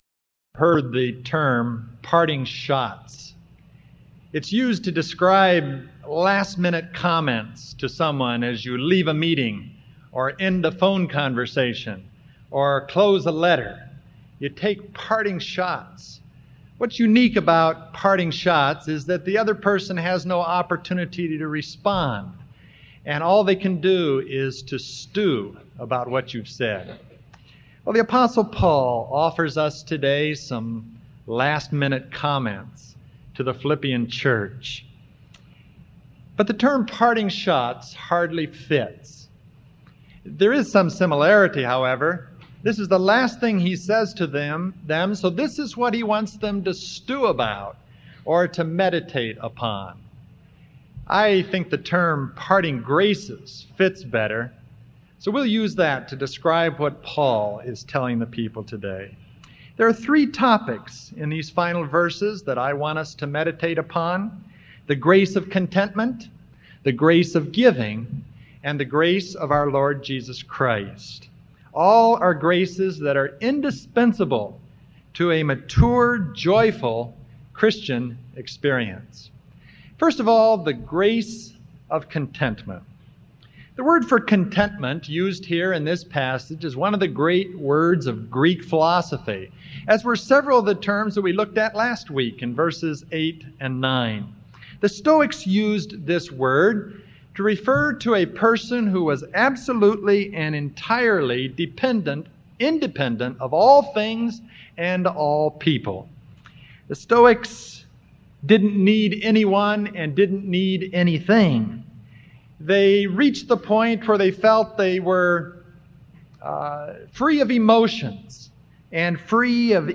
I hope that’s the case for us here this morning. There are three topics in these final verses he wants us to meditate upon: the grace of contentment, the grace of giving, and the grace of the Lord Jesus Christ.